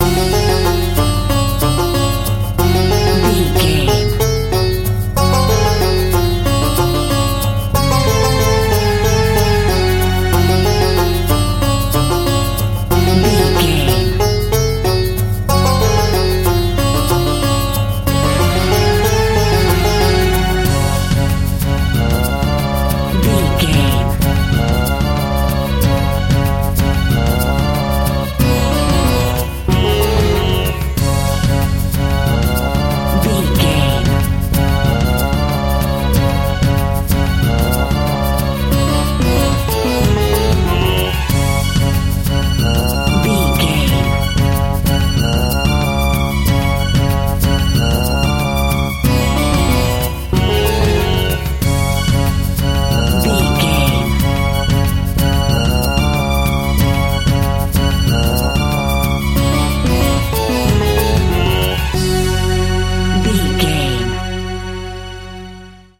Aeolian/Minor
F#
World Music
percussion